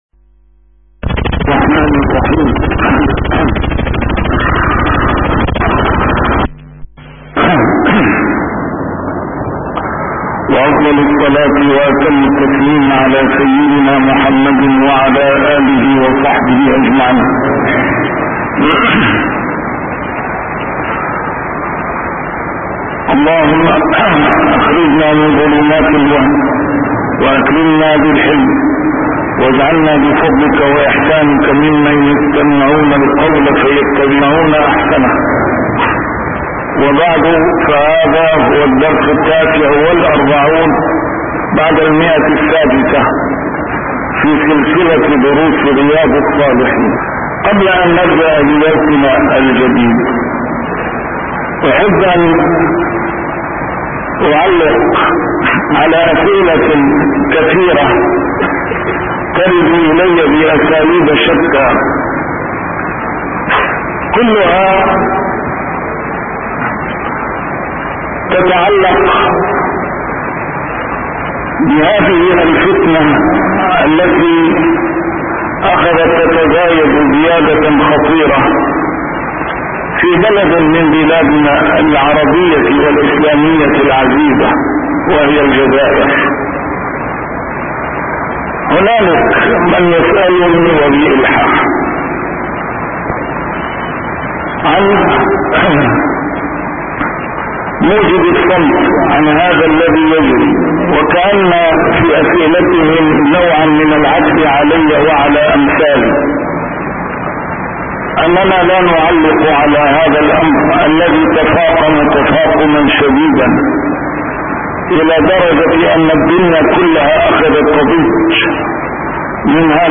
A MARTYR SCHOLAR: IMAM MUHAMMAD SAEED RAMADAN AL-BOUTI - الدروس العلمية - شرح كتاب رياض الصالحين - 649- شرح رياض الصالحين: آداب الشرب